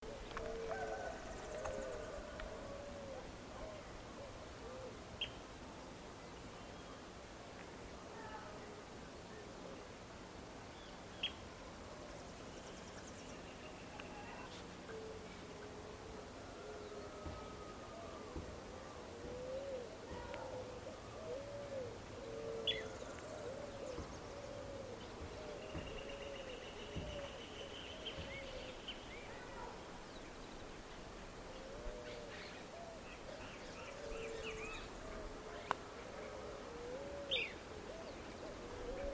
Fiofío Pico Corto (Elaenia parvirostris)
Contacto. Vocalizando desde un talar.
Nombre en inglés: Small-billed Elaenia
Certeza: Observada, Vocalización Grabada